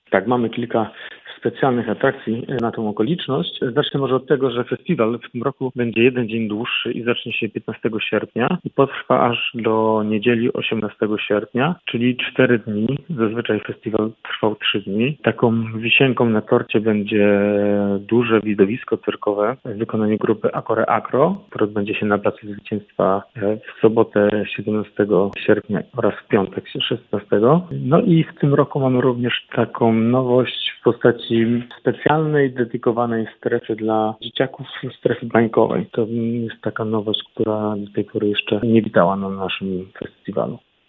Na naszej antenie zdradził, co wyjątkowego przygotowano w programie tegorocznej edycji festiwalu z racji jubileuszu.